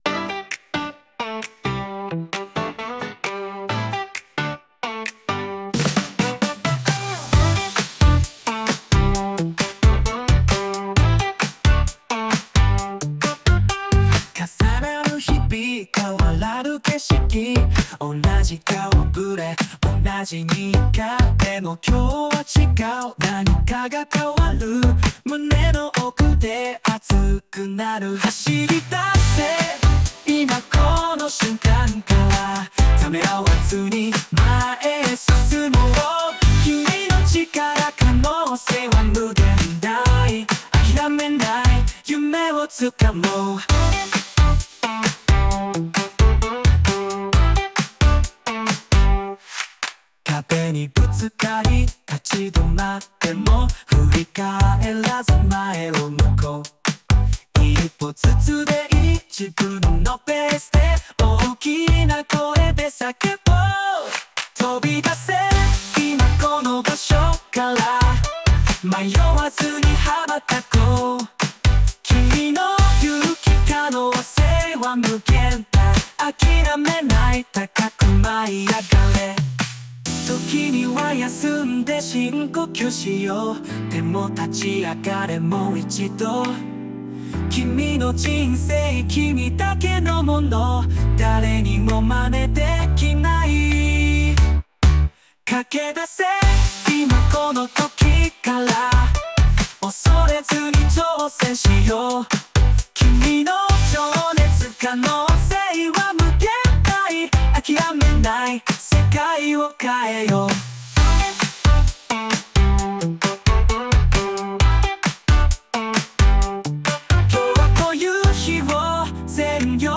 男性ボーカル（邦楽・日本語）曲です。